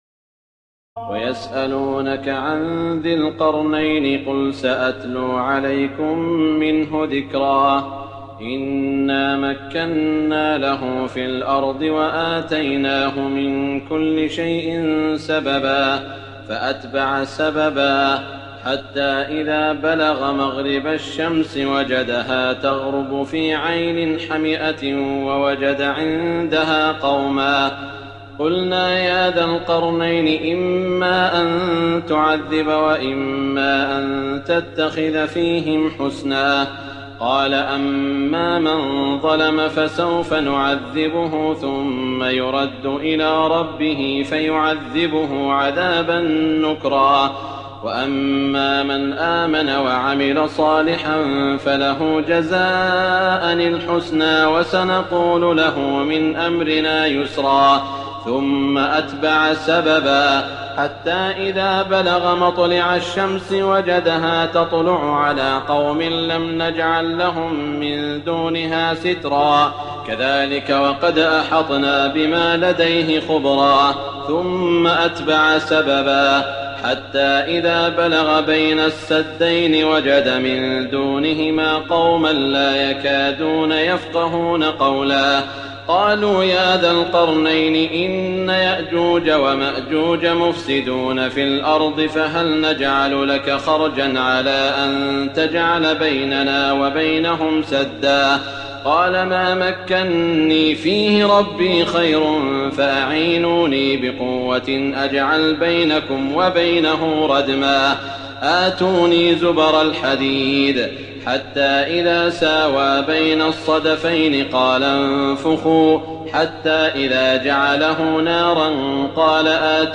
تراويح الليلة الخامسة عشر رمضان 1419هـ من سورتي الكهف (83-110) و مريم كاملة Taraweeh 15 st night Ramadan 1419H from Surah Al-Kahf and Maryam > تراويح الحرم المكي عام 1419 🕋 > التراويح - تلاوات الحرمين